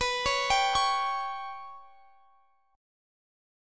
Listen to Bsus2#5 strummed